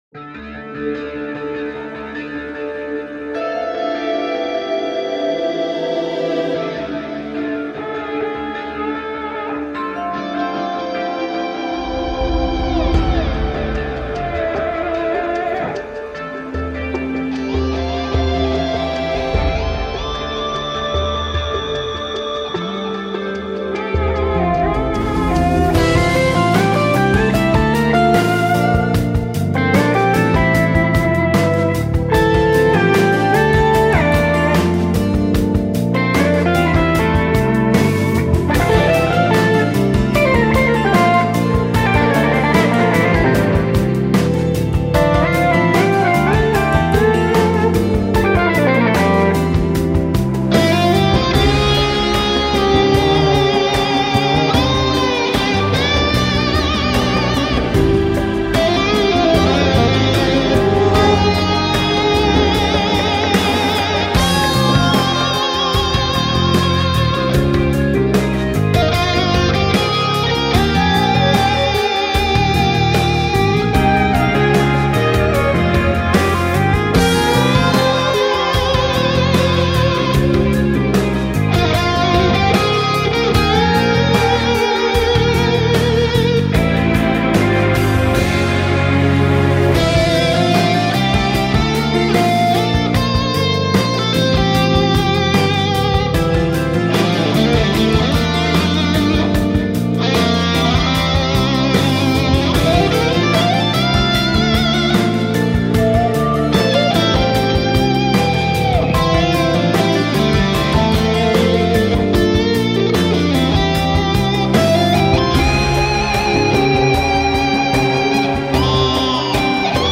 This is one of the instrumentals-